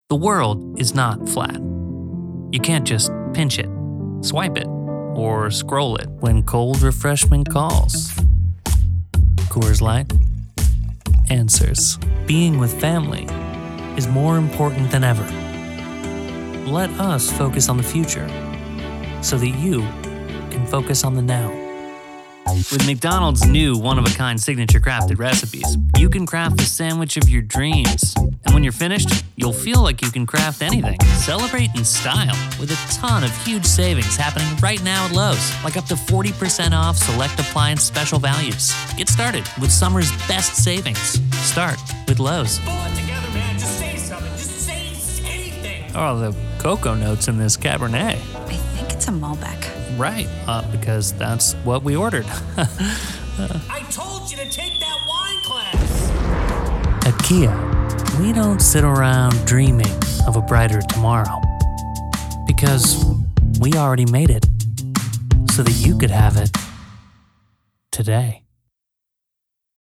Voiceover : Commercial : Men